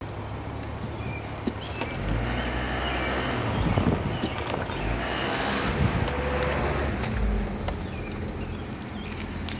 1998 V70r Clicking Noise on Acceleration
It only happens at like zero to 5-7 maybe 10mph. It happens both if turning in a parking lot or going straight from a stop light.